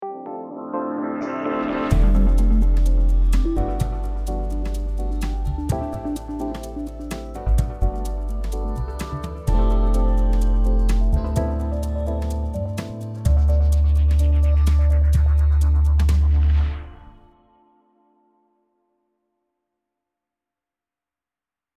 Podcast Intro Music